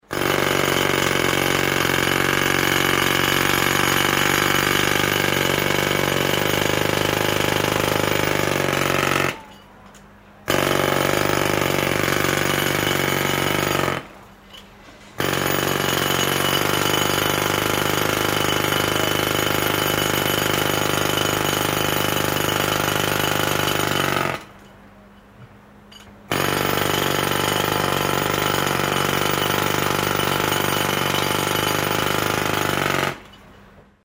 Звуки отбойного молотка
Раздражающий звук отбойного молотка